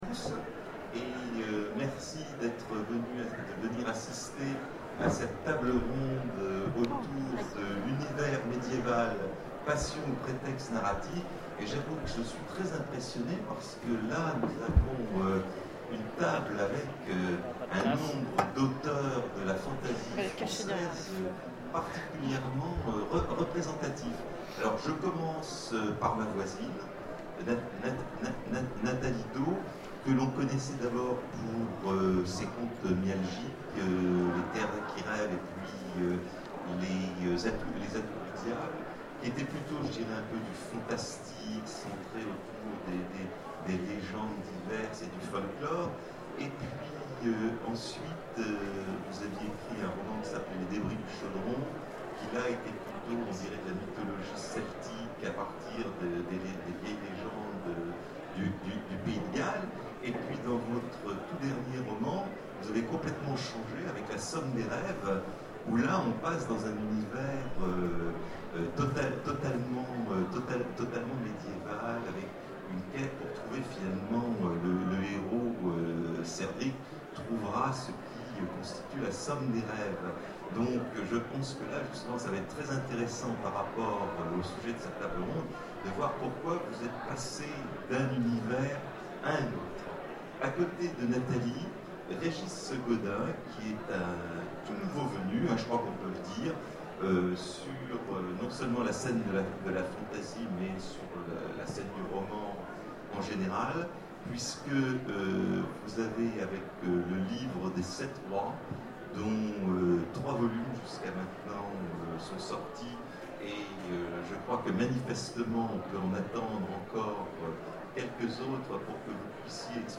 Zone Franche 2014 : Conférence Univers médiéval, passion ou prétexte narratif